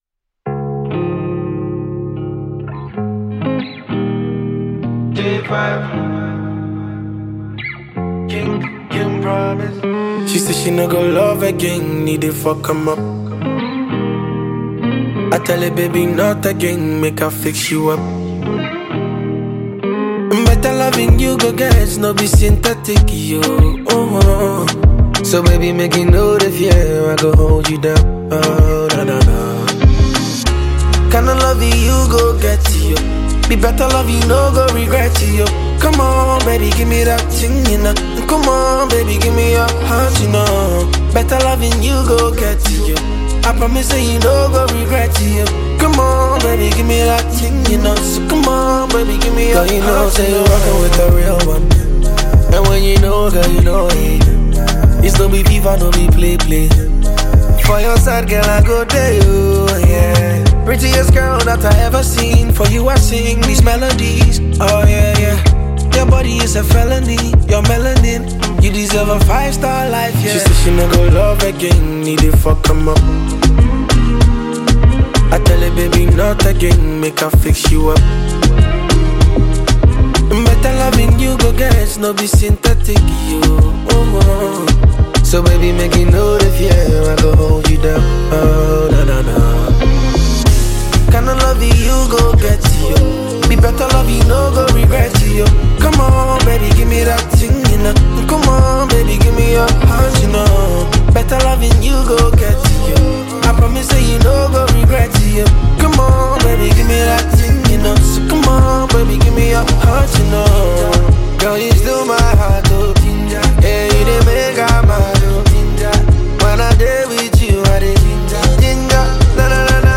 Ghanaian highlife and afrobeats singer-songwriter
African Music